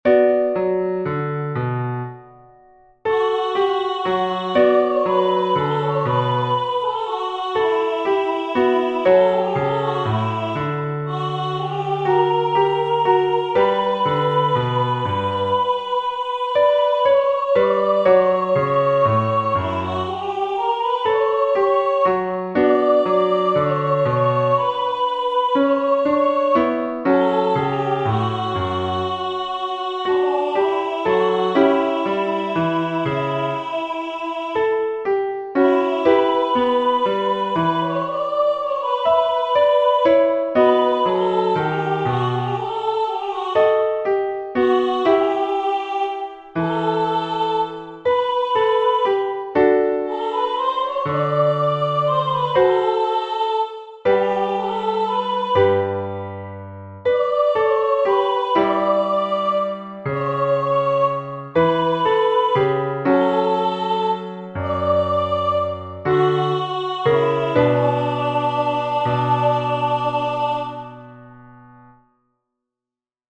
Entoación con acompañamento
Melodía e acompañamento: